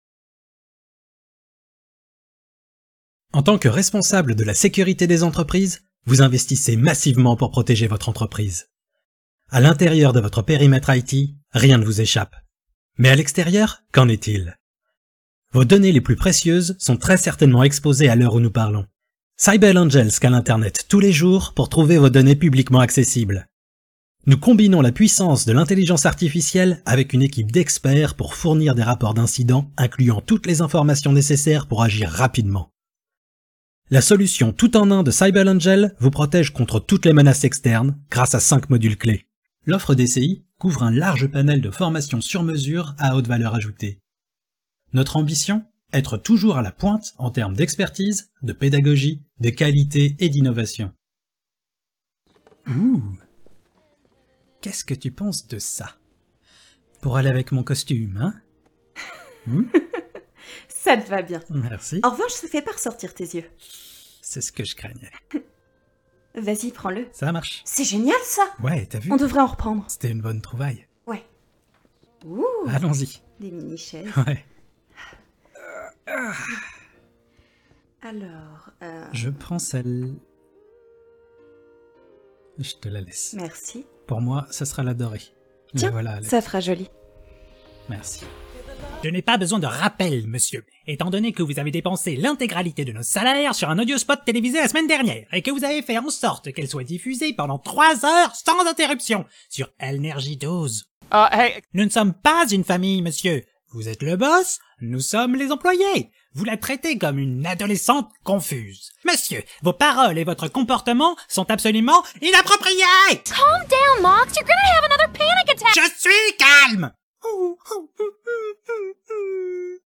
Voix off
Bande démo
28 - 46 ans - Ténor